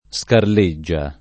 vai all'elenco alfabetico delle voci ingrandisci il carattere 100% rimpicciolisci il carattere stampa invia tramite posta elettronica codividi su Facebook scarlea [ S karl $ a ] o scarleggia [ S karl %JJ a ] (lett. sclarea ) s. f. (bot.)